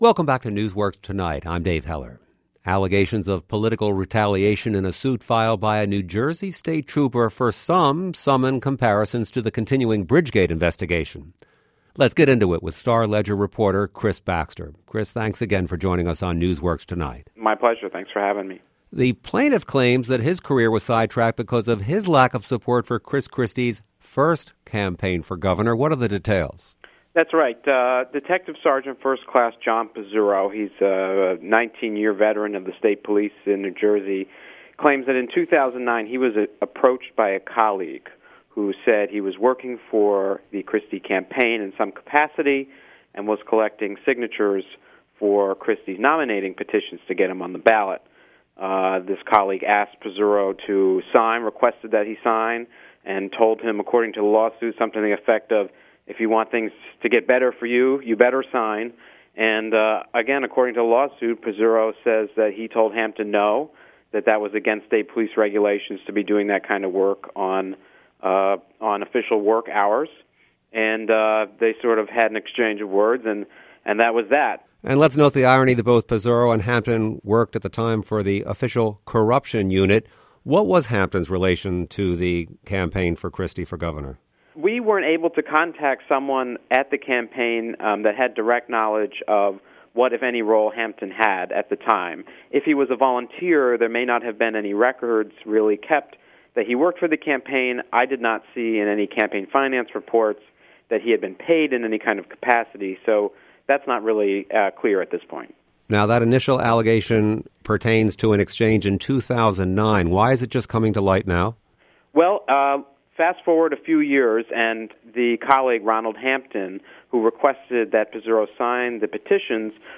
NewsWorks Tonight was a daily radio show and podcast that ran from 2011-2018.